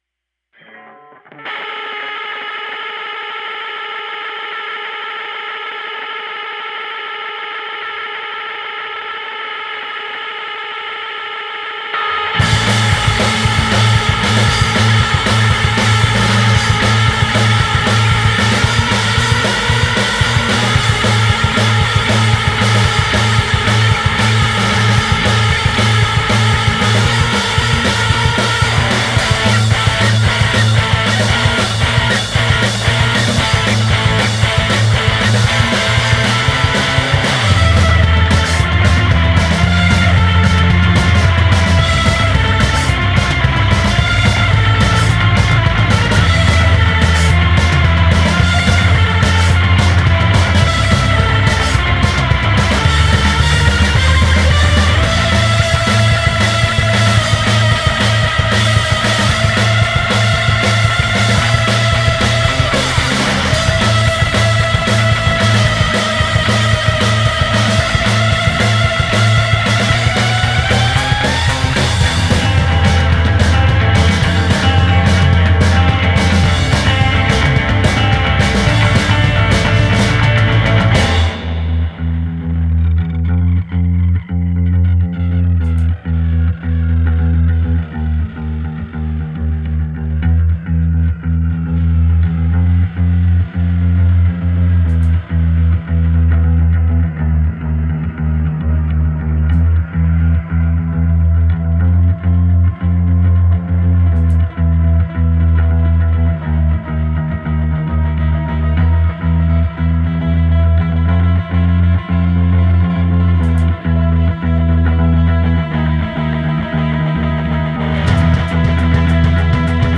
weird powerful rock post-Michael Jackson - pré-apocalypse